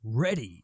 Ready 2.wav